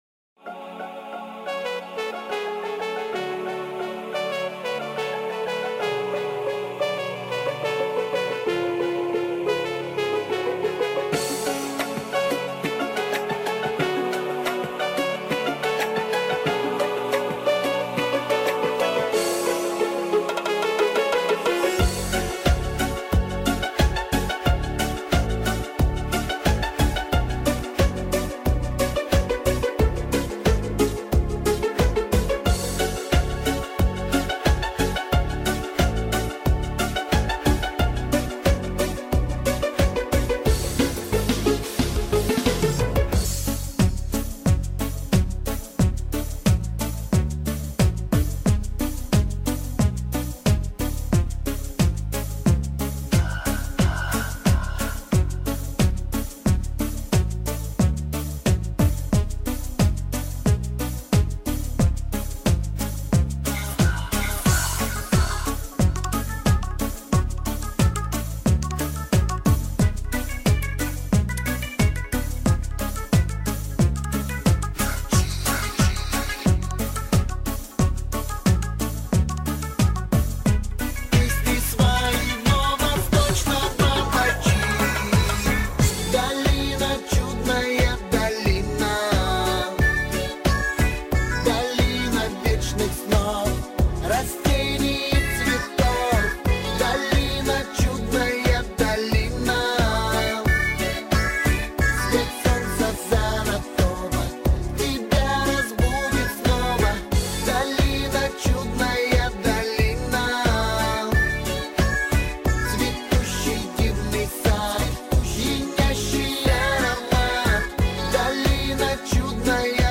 минусовка версия 54225